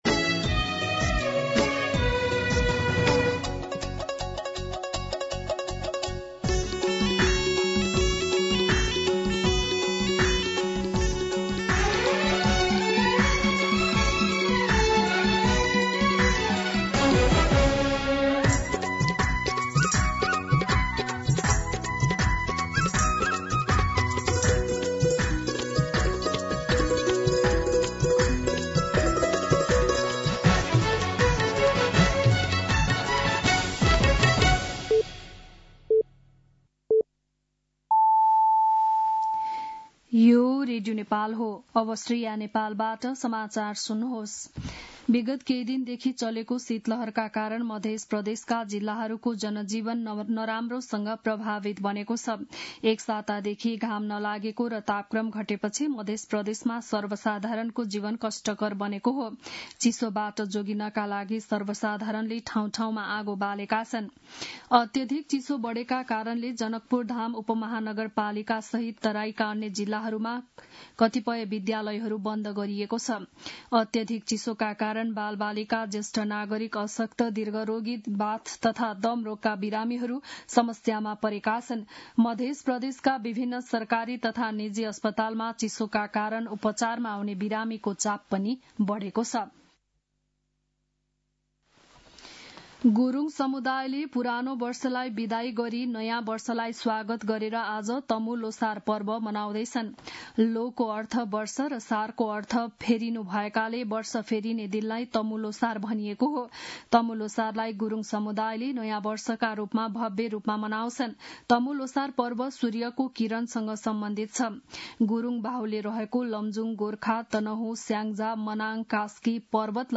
बिहान ११ बजेको नेपाली समाचार : १५ पुष , २०८२
11am-News-15.mp3